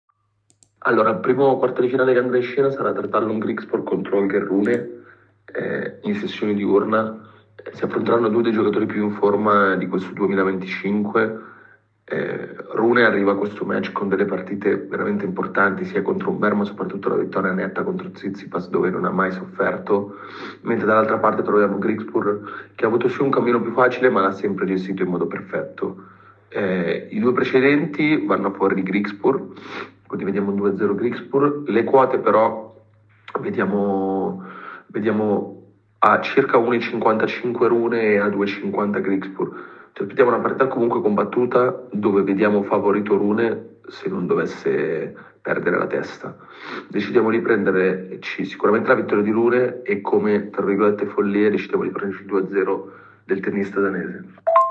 ha analizzato questo match in un veloce audio con i migliori consigli per le scommesse di Tennis.